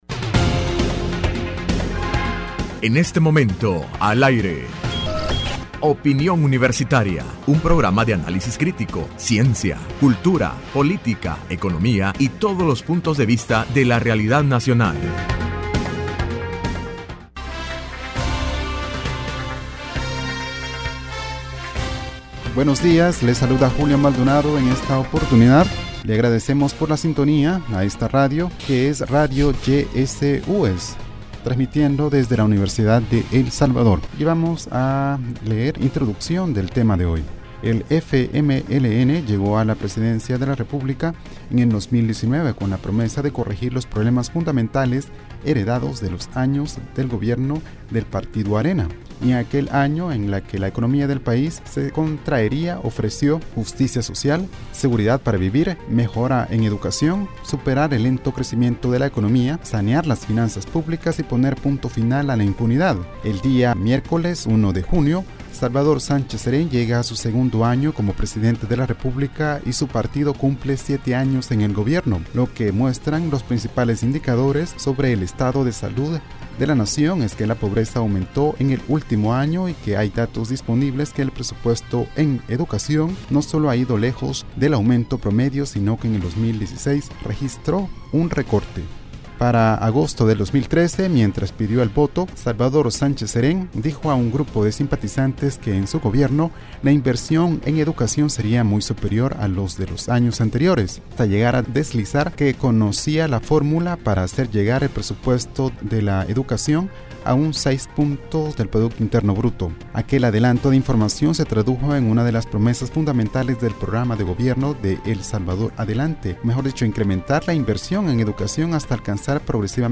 Entrevista Opinión Universitaria (2 Junio 2016) : Evaluación del sistema educativo salvadoreño